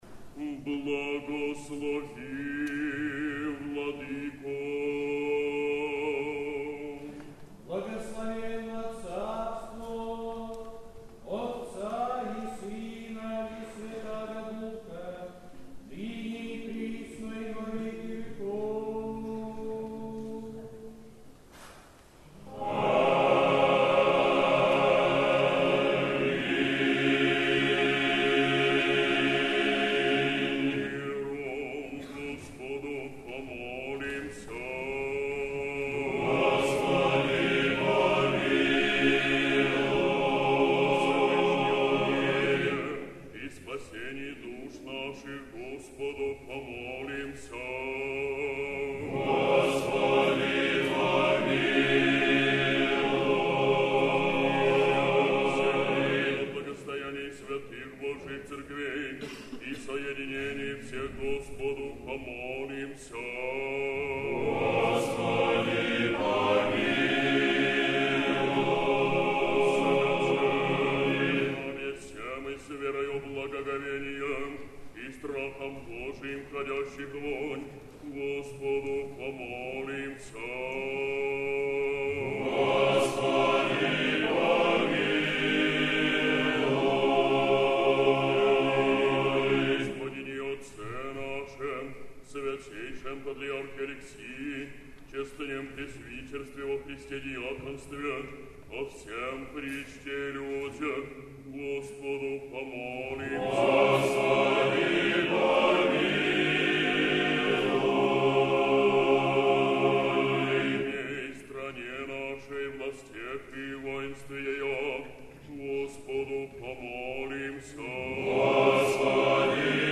Сретенский монастырь. Литургия. Хор Сретенского монастыря.
Богослужение в День Святой Троицы